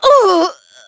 Xerah-Vox_Damage_01_Madness.wav